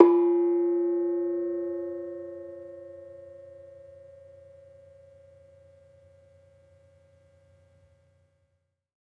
Bonang-F3-f.wav